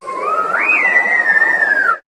Cri de Tokopisco dans Pokémon HOME.